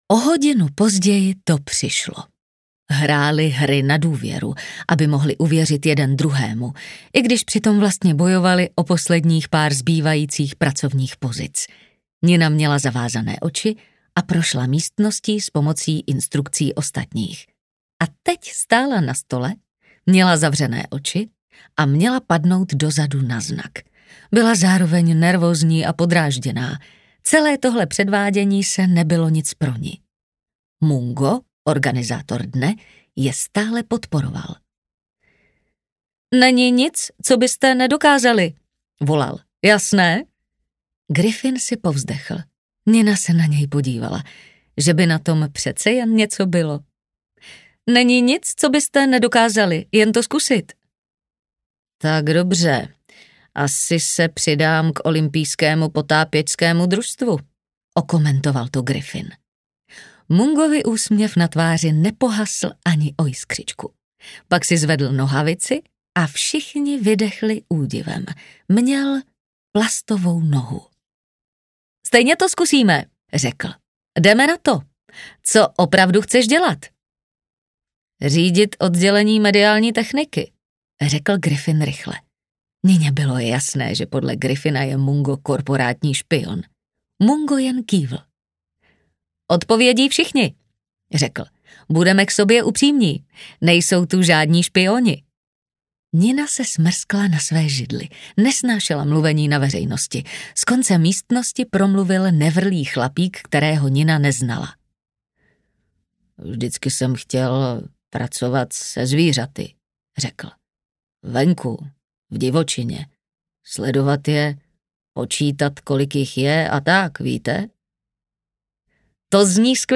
Pojízdný krámek snů audiokniha
Ukázka z knihy